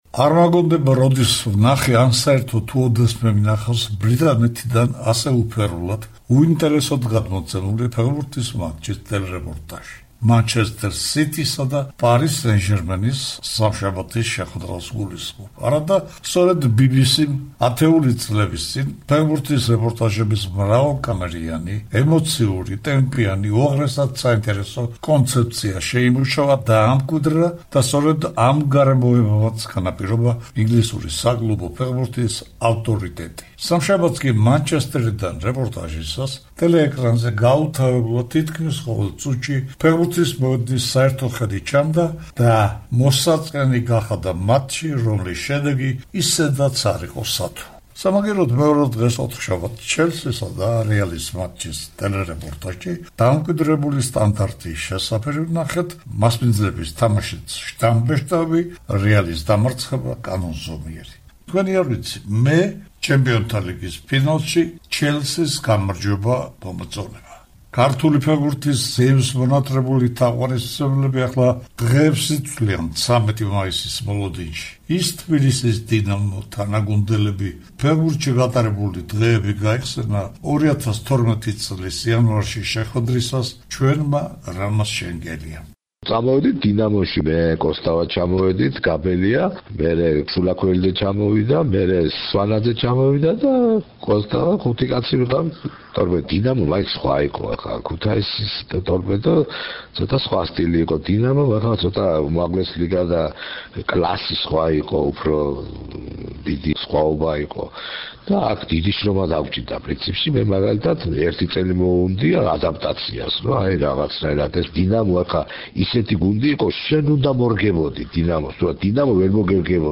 რამაზ შენგელიამ, 1981 წლის 13 მაისს დუსელდორფში ევროპის ქვეყნების თასების მფლობელთა თასის გათამაშების ფინალურ მატჩში გამარჯვებული თბილისის დინამოს ცენტრფორვარდმა, თანაგუნდელები, დინამო, კარიერის საინტერესო ეპიზოდები გაიხსენა 2012 წლის იანვრის ინტერვიუში, რომლის ფრაგმენტებს მოისმენენ...